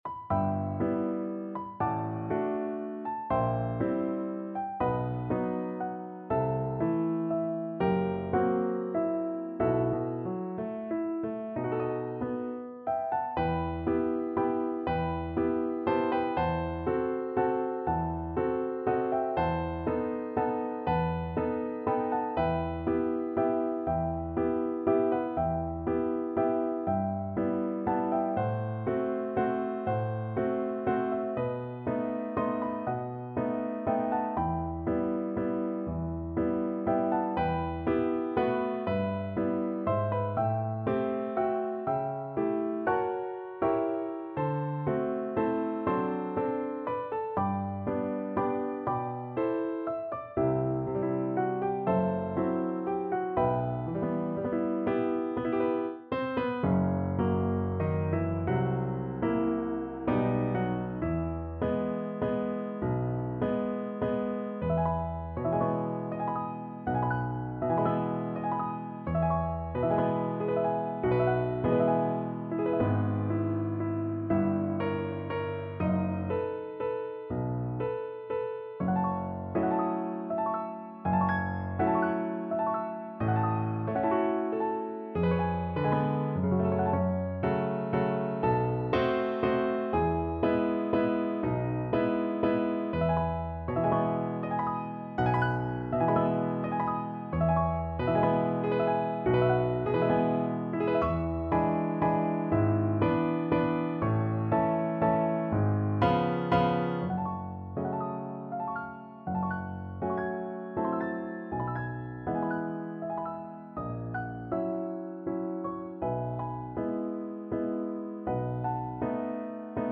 3/4 (View more 3/4 Music)
~ = 120 Lento
Classical (View more Classical Voice Music)